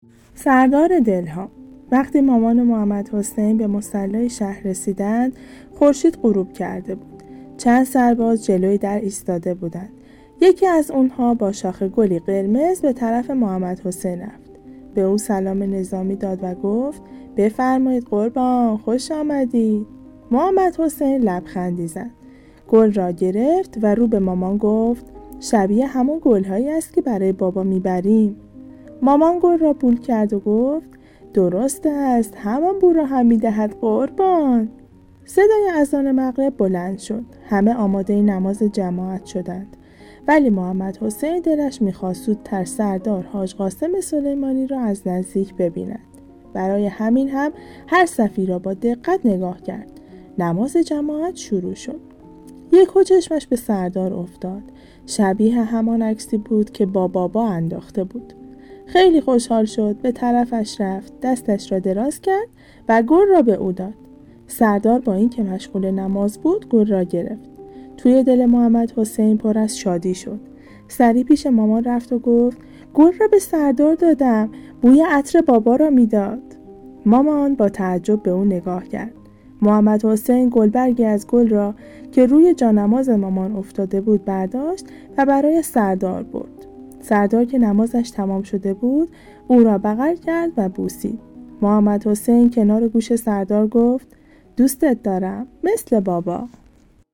داستان_صوتی جشنواره_دانایی_برای_توانایی